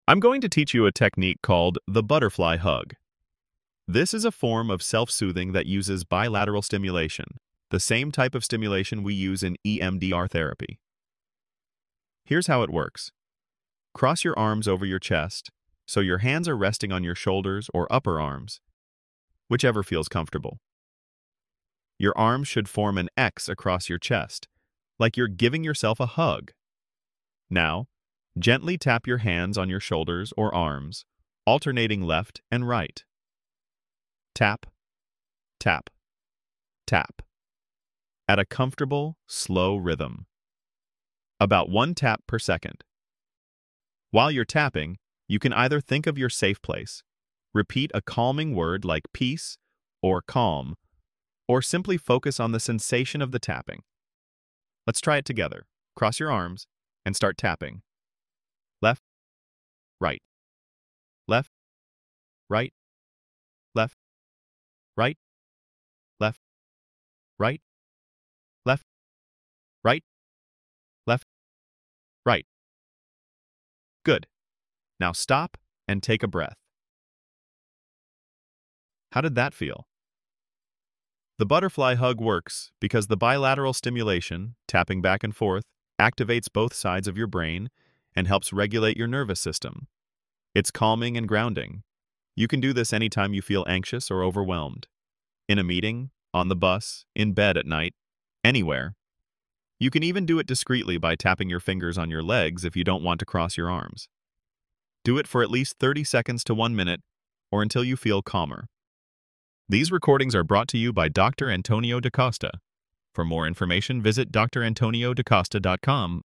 Free audio-guided exercises for EMDR Phase 2 preparation